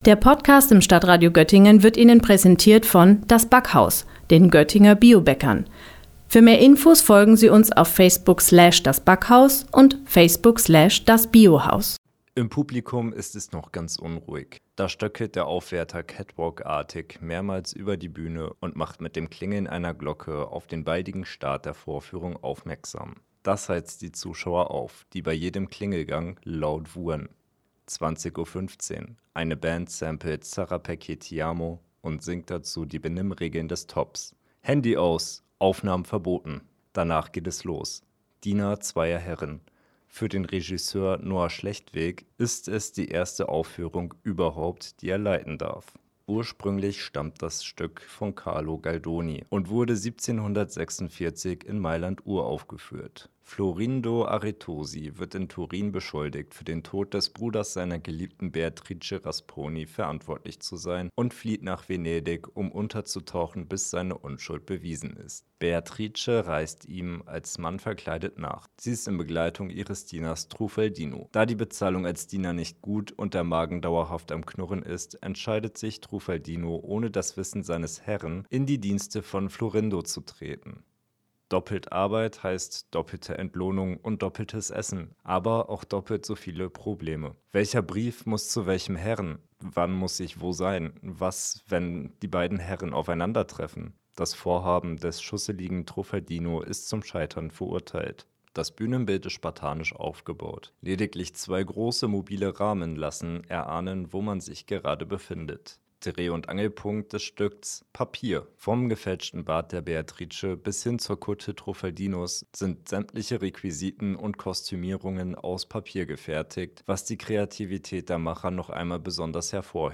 Beiträge > Rezension: Diener zweier Herren im ThOP - StadtRadio Göttingen